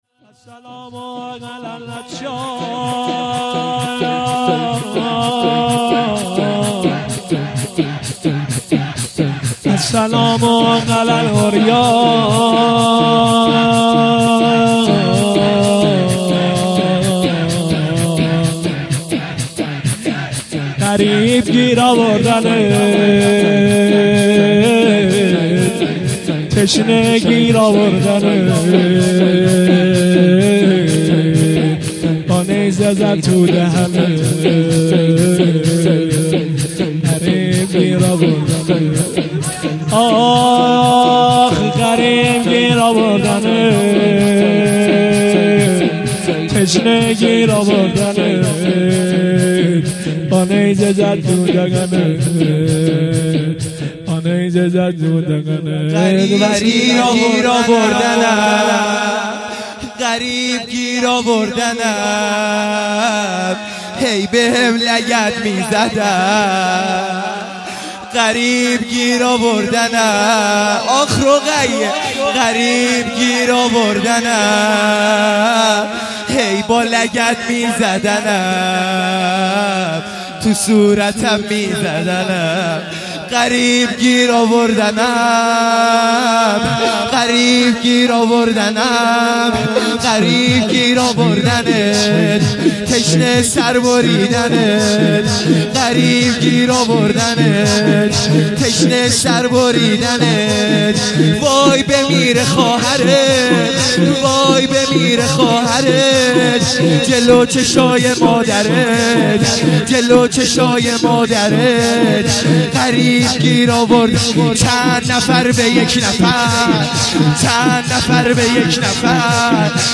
لطمه زنی | السلام علی العطشان